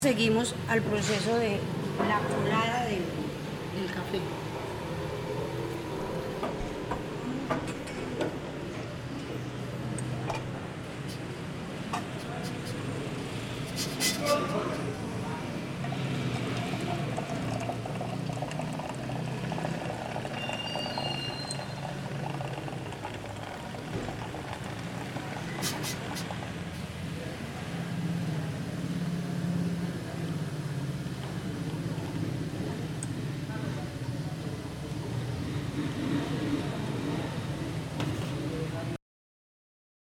Registro sonoro del proceso de producción de café en Trujillo, Valle del Cauca. Fase de colar el café.